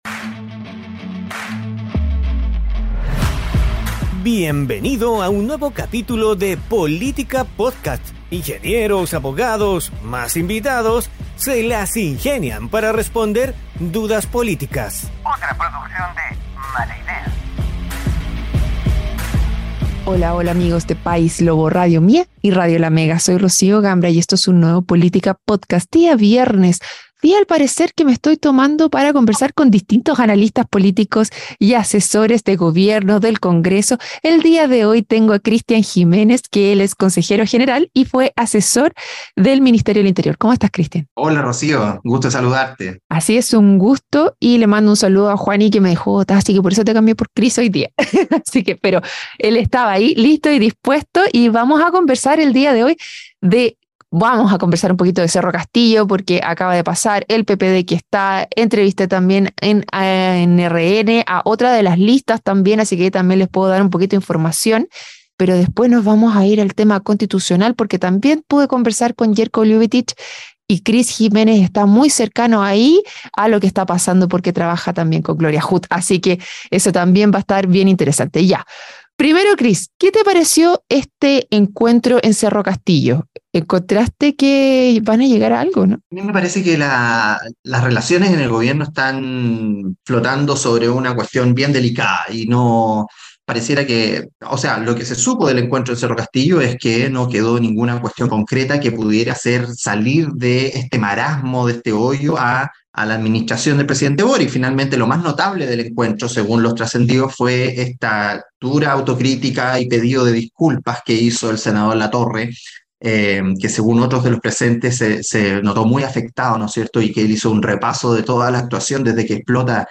junto a un panel de expertos estables e invitados especiales